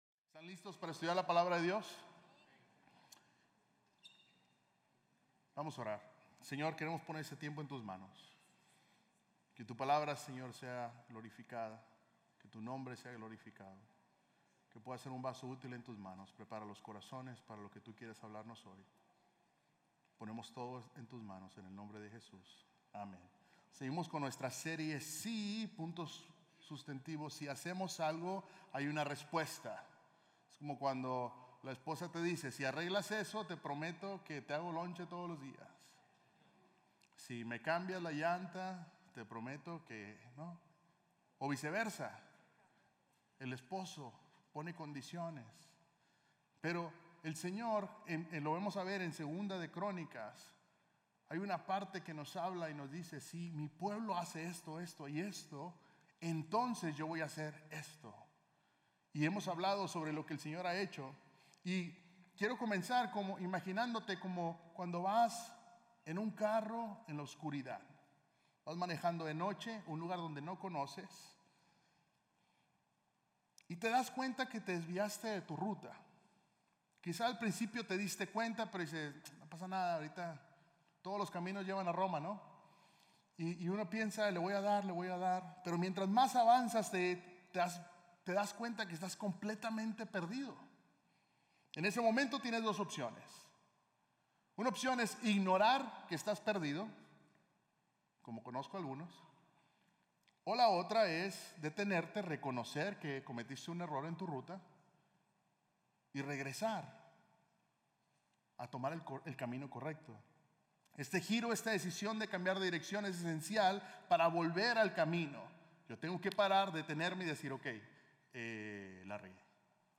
Sermones North Klein – Media Player